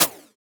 edm-perc-23.wav